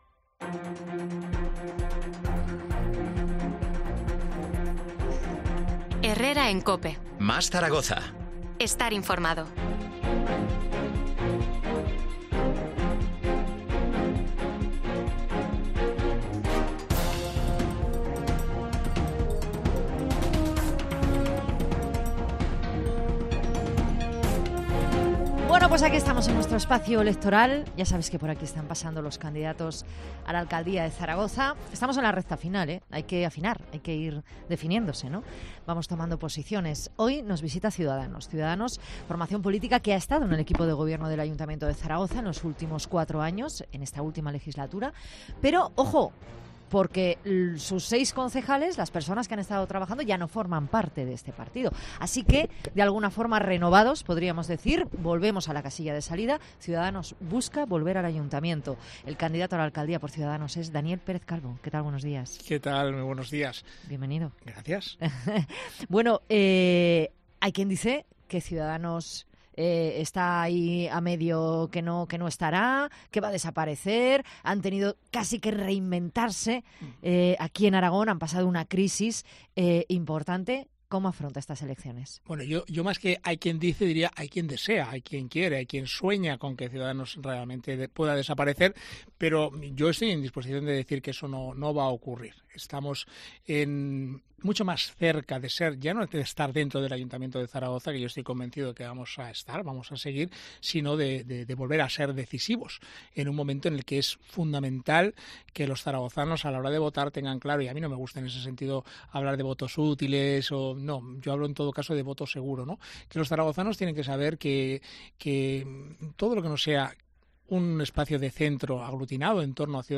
El candidato de Ciudadanos a la alcaldía de la capital ha pasado este miércoles por los estudios de COPE Zaragoza para exponer cuáles son las...
Entrevista a Daniel Pérez Calvo, candidato a la alcaldía de Zaragoza por Ciudadanos